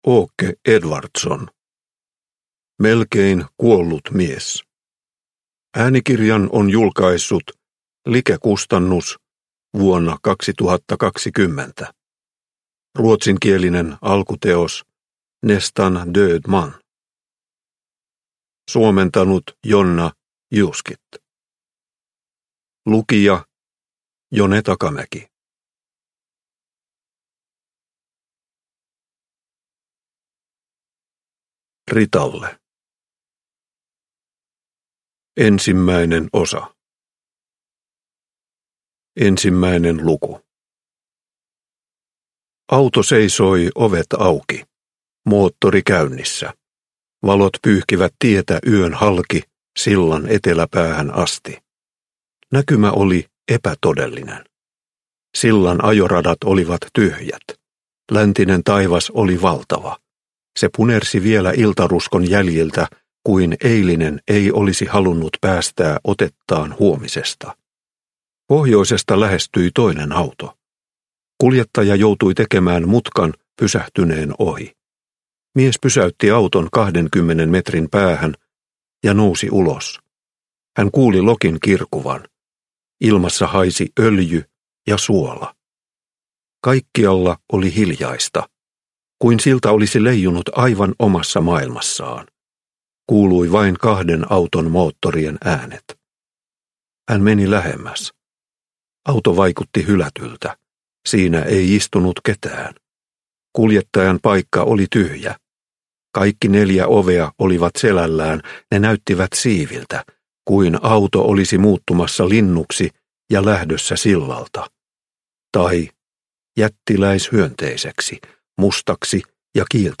Melkein kuollut mies – Ljudbok – Laddas ner